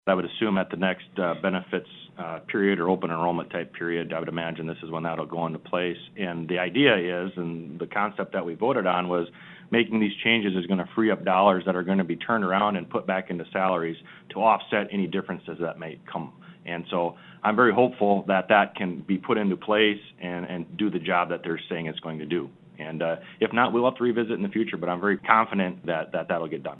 South Dakota House leadership recap 2021 Legislative Session during a March 11, 2021, news conference.